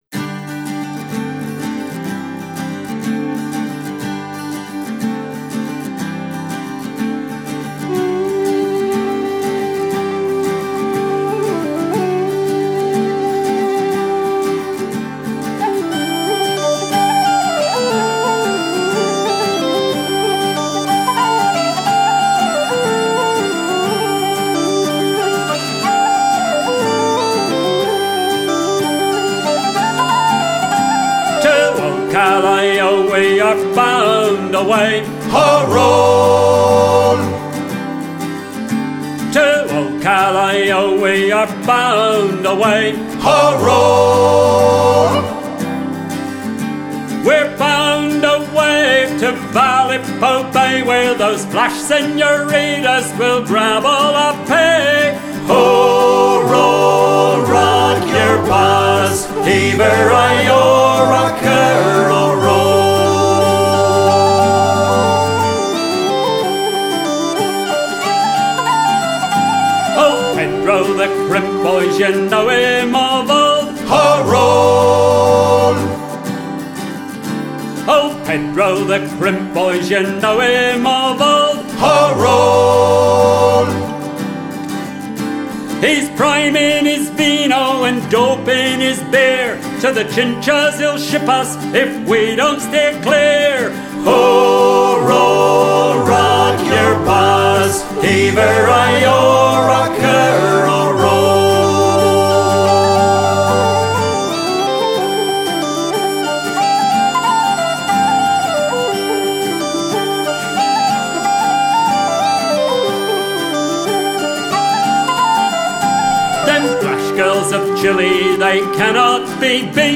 A capstan shanty, from the South American guano trade.